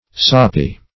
Soppy \Sop"py\, a.